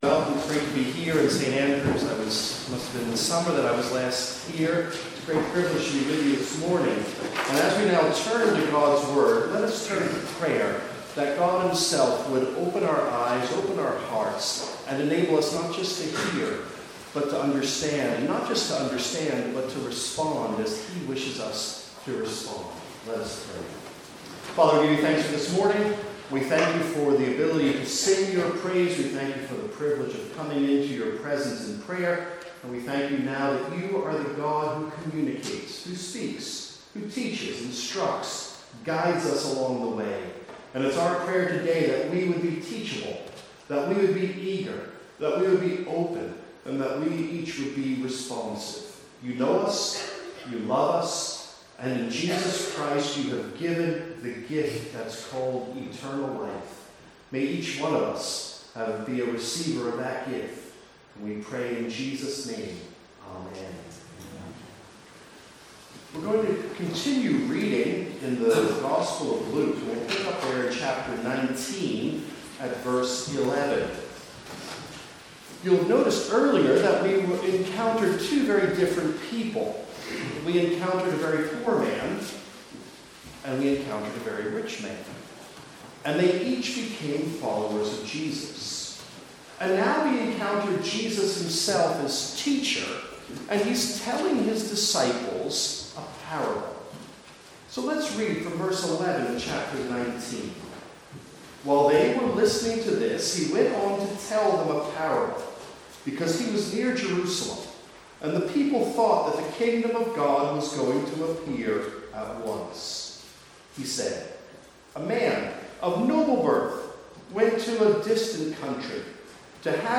Sermons | St Andrews Free Church
From the morning service on Giving Sunday 2015.